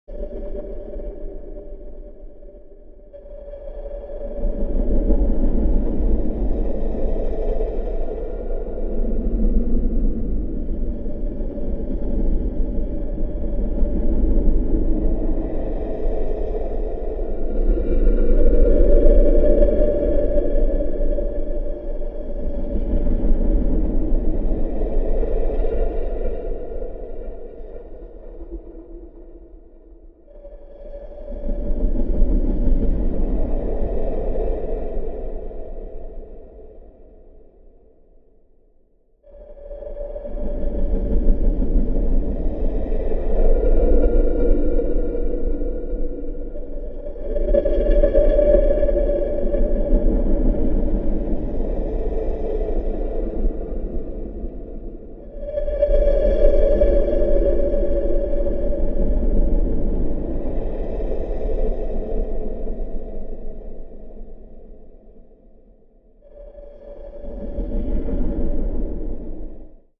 Gravity Drive eerie, subterranean tones with echoes